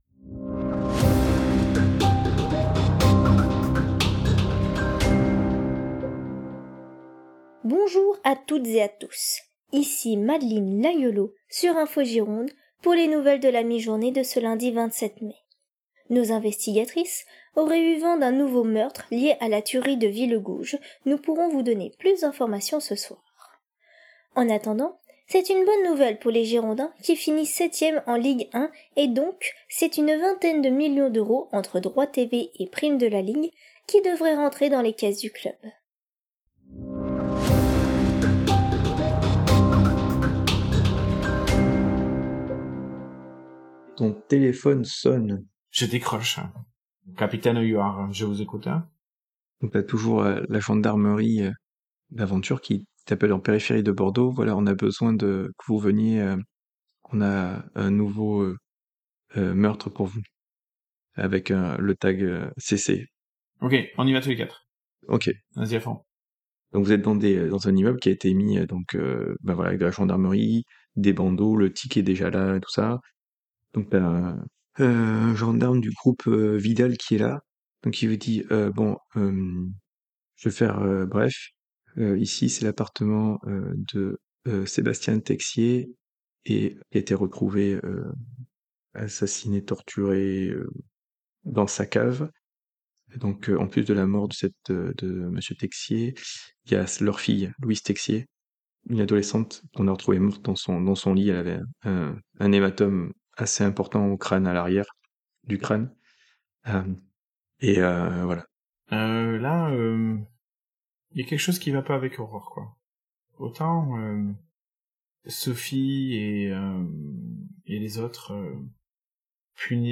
dialing numbers.wav
ringing tone, answering, then hanging up
cell phone hang up
Gendarmerie, sirène extérieure véhicule